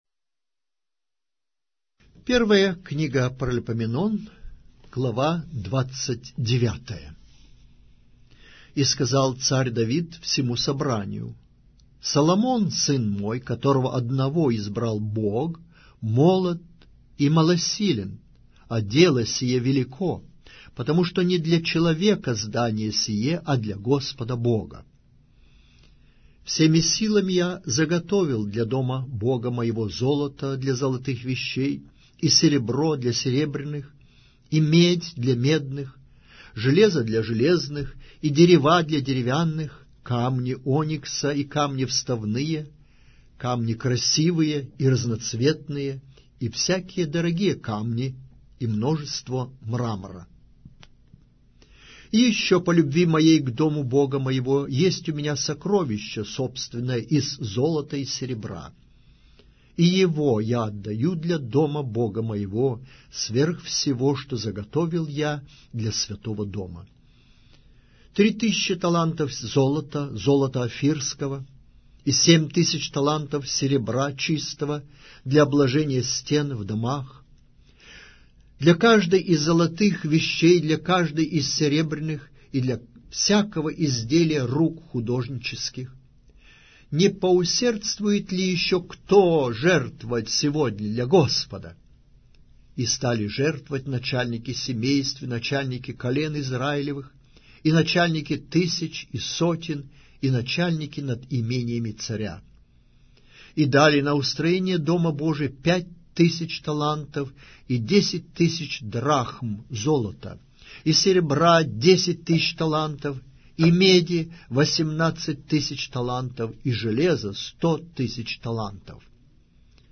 Аудиокнига: 1-я Книга Паралипоменон